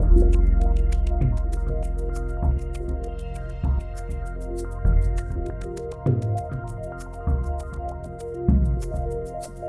LightPercussiveAtmo6_99_C.wav